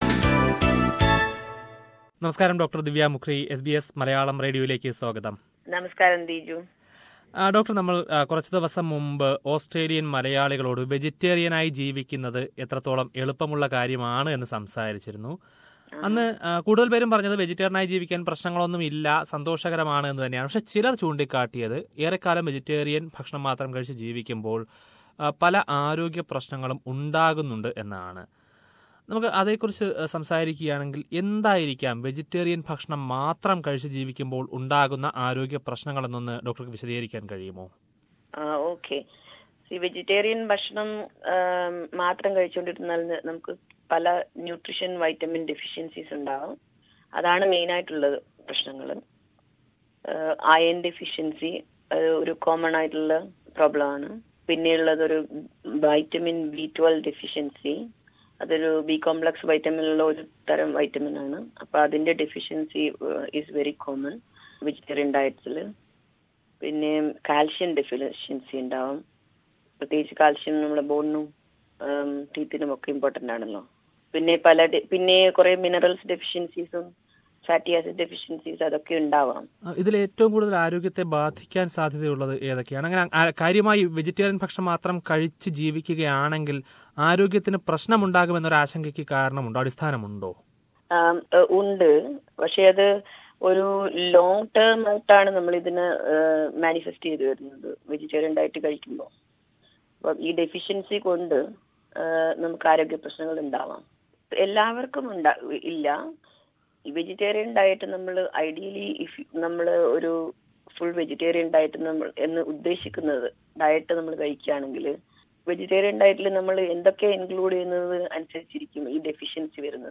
Last week we organised an interview with a GP on the food habits of vegans.